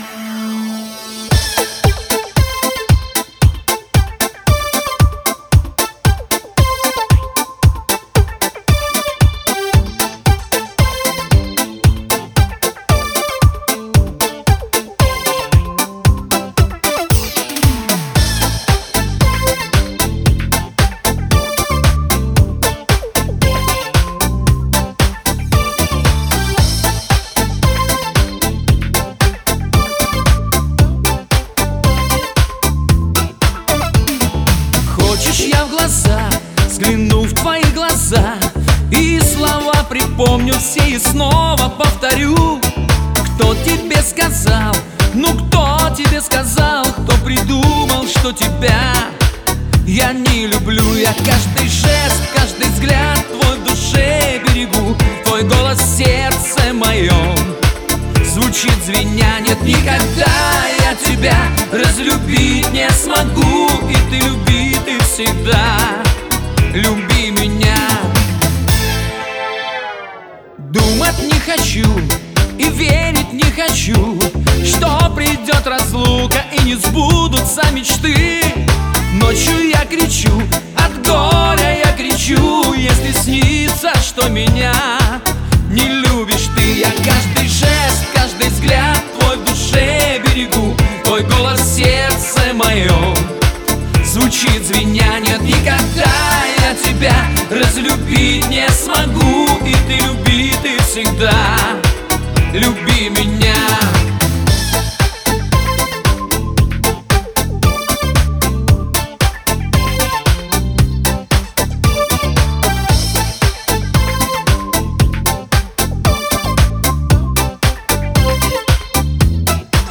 вокал, гитара
альт-саксофон, клавишные
ударные, перкуссия